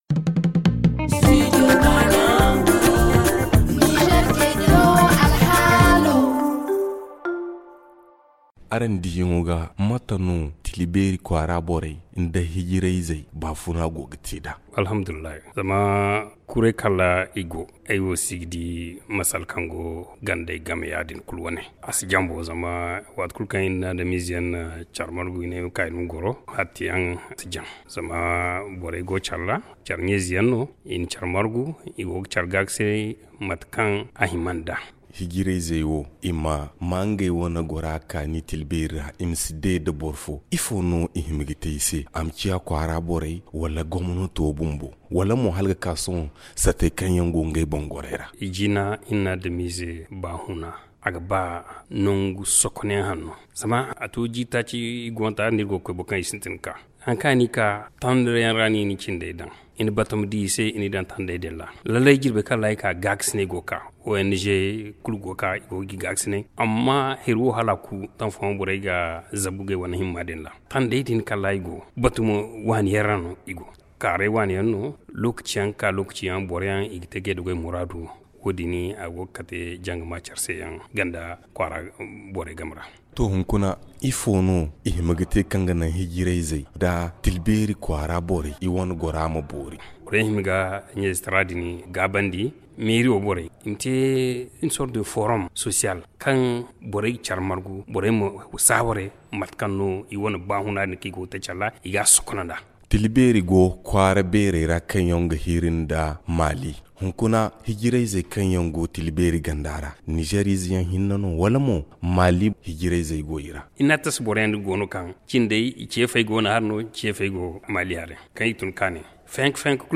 Le magazine en zarma